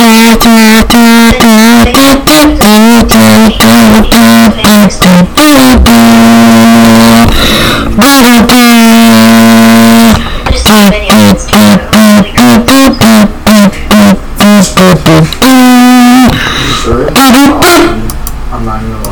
Category 😂 Memes